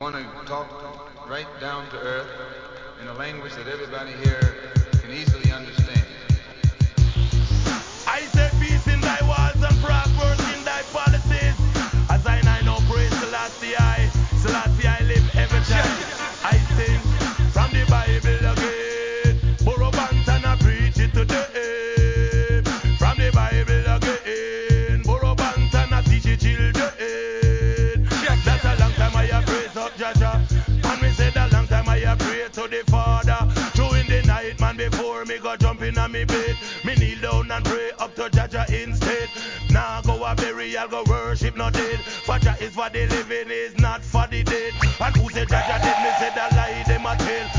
REGGAE
癖になる怒渋RHYTHMです!!